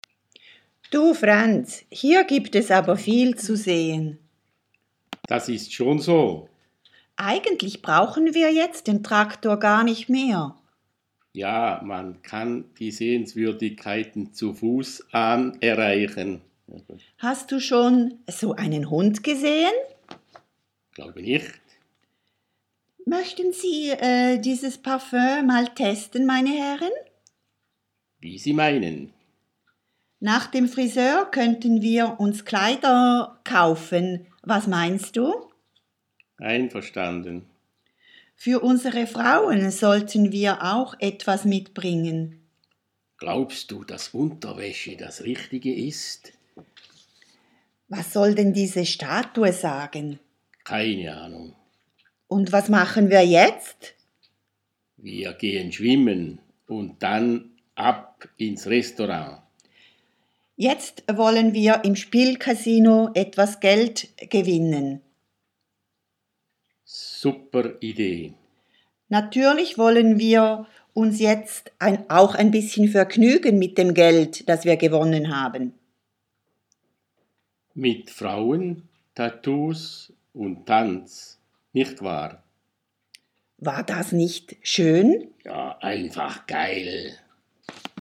Man könnte vielleicht auch einen Dialog schreiben zum Video.